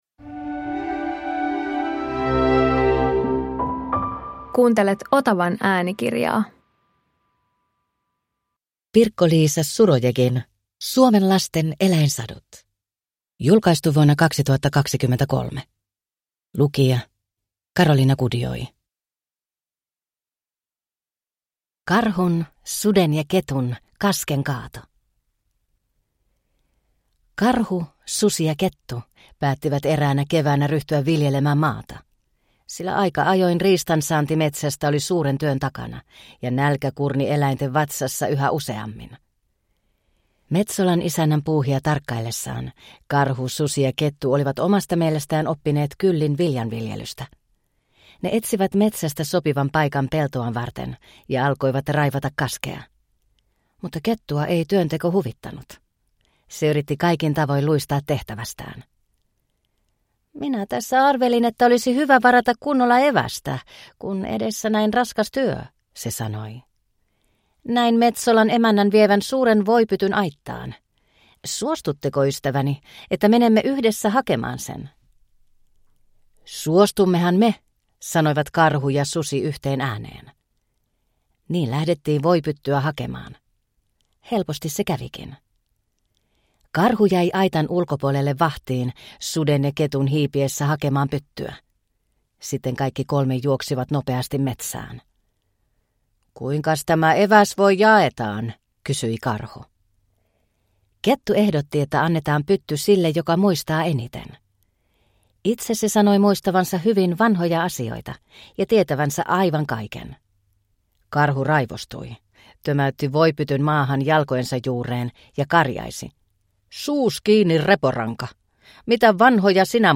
Suomen lasten eläinsadut – Ljudbok – Laddas ner
Uppläsare: